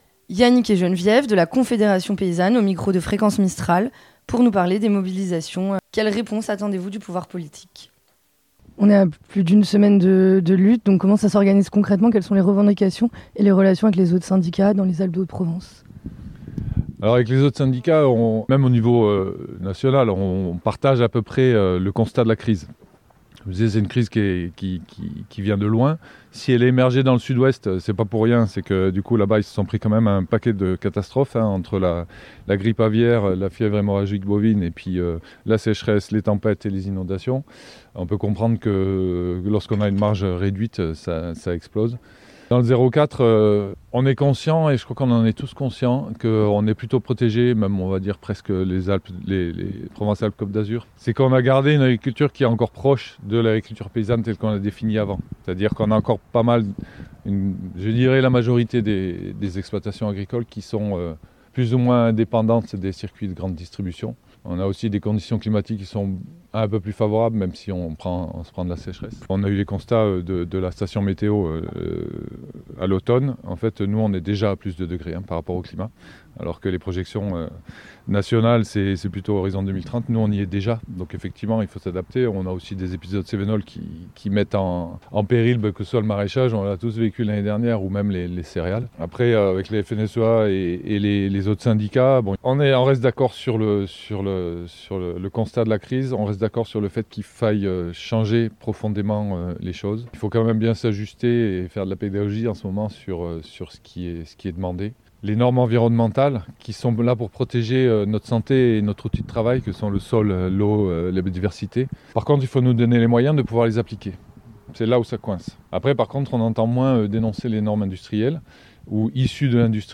Conférence de presse - Confédération paysanne - Mesures politiques
Le 30 janvier se tenait la conférence presse de la Confédération Paysanne 04 afin d’évoquer la situation du monde en France, en Europe et aussi dans les Alpes de Haute Provence. Celle ci s’est déroulée sur une ferme des Mées, le Gaec du Champ des Anes, un exemple du modèle d’agriculture défendue par le syndicat : L’agriculture paysanne, une agriculture résiliente, autonome, proche des citoyens et prévue pour durer.